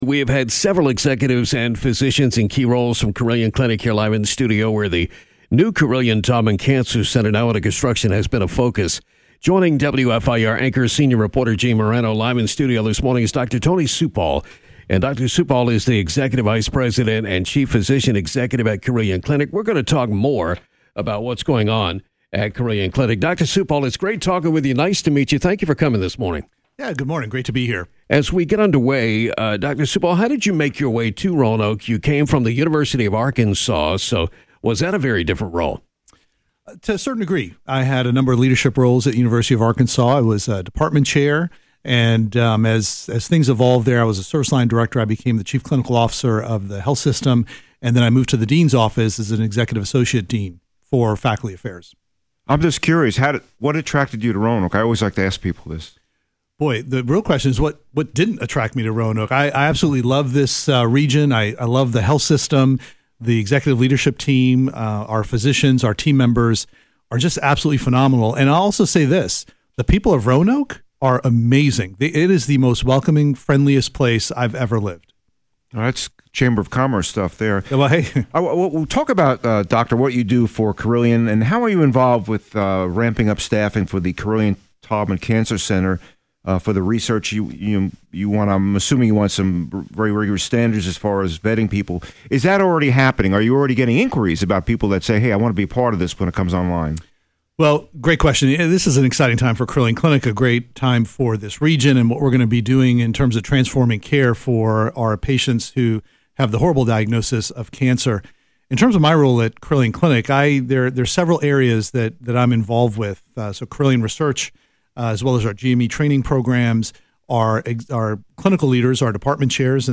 We’ve had several executives and physicians in key roles from Carilion Clinic here live in studio, where the new Carilion Taubman Cancer Center now under construction has been a focus.
Hear the complete conversation below – or watch it on the WFIR News Facebook page.